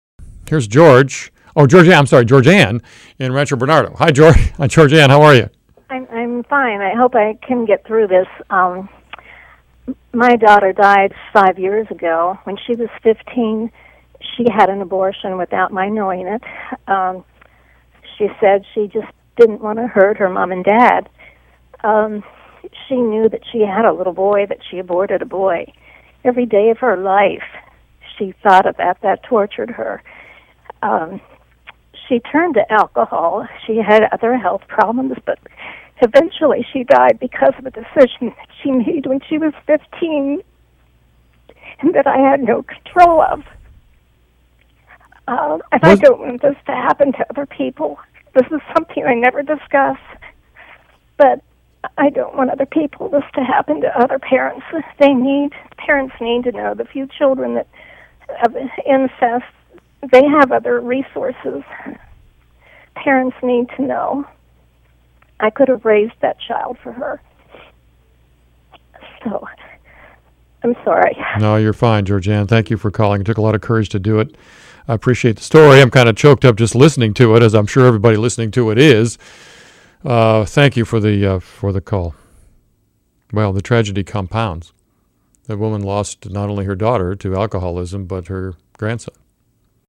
AMothersTestimony.mp3